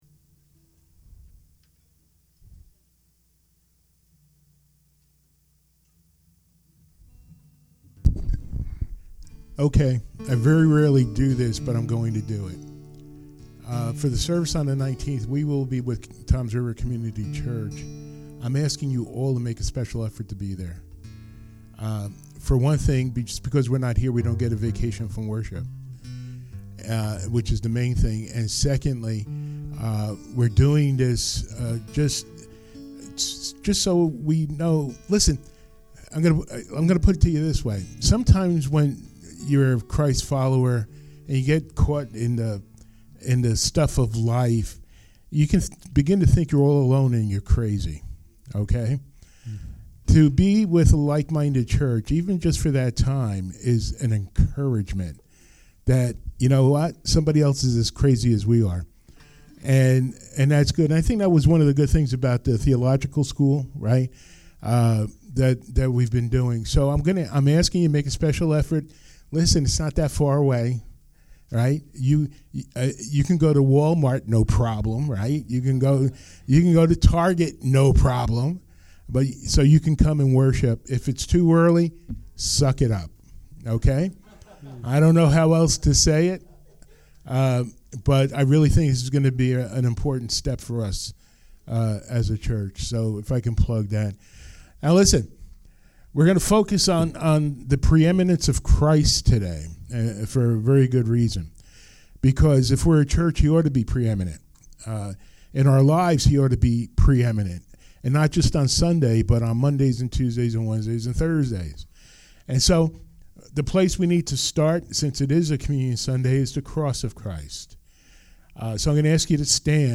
Colossians 1:1-10 Service Type: Sunday Morning Worship Intro